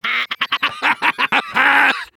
soldier_LaughLong01.mp3